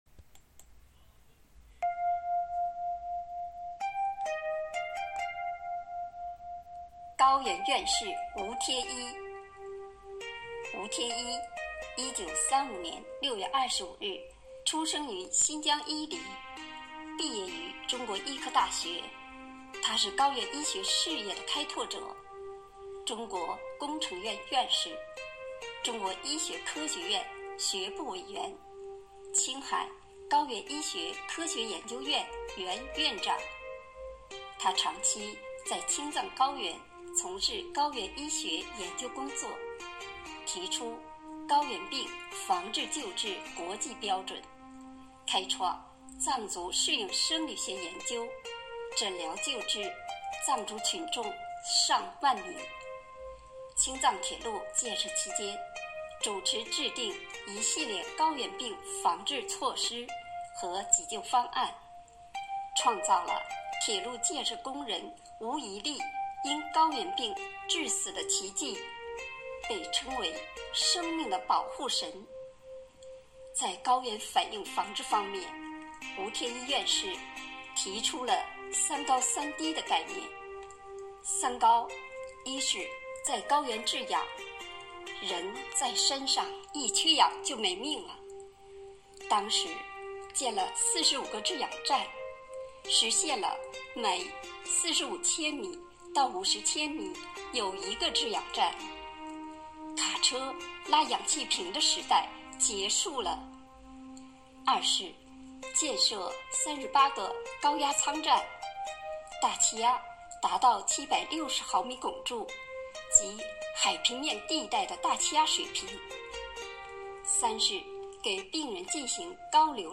五一劳动节来临之际，为致敬最美劳动者，4月28日，生活好课堂幸福志愿者魅力之声朗读服务（支）队举办“致敬最美劳动者 一一我心中的故事”云朗诵会。